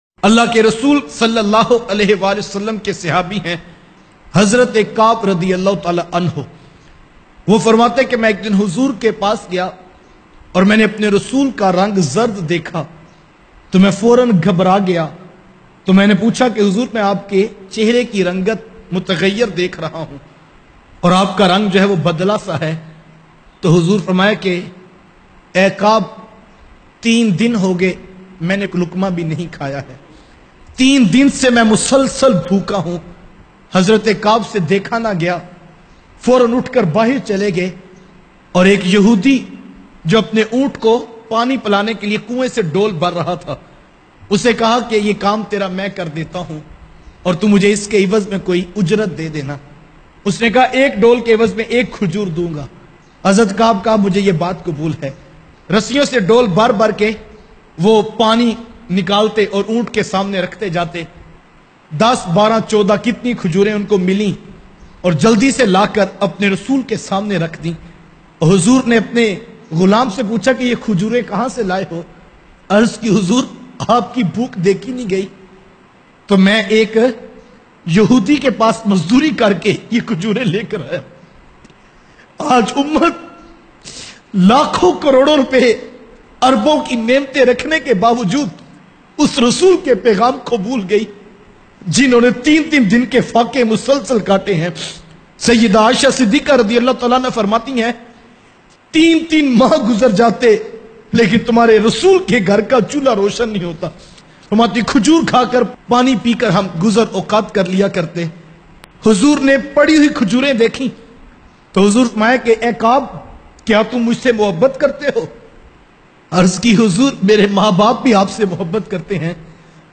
Pyare Huzur or Hazrat Qab bin siddik bayan mp3